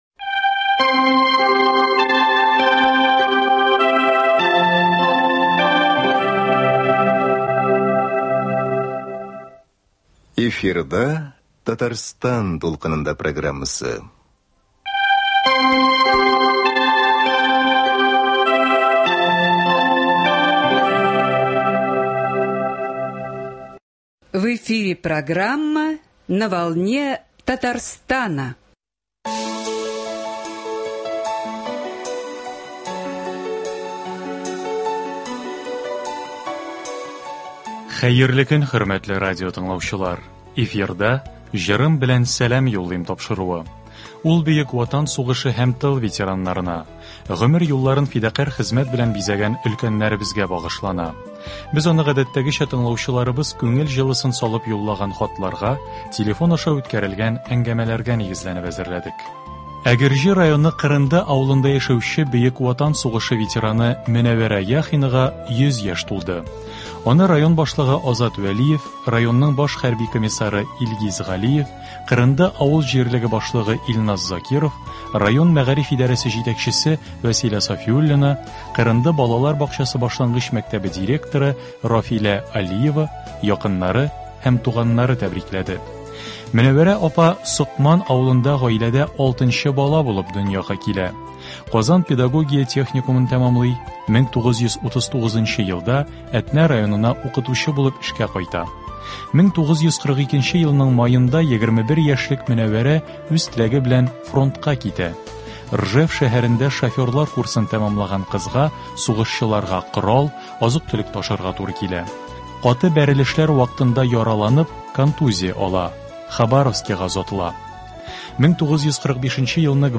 музыкаль программасы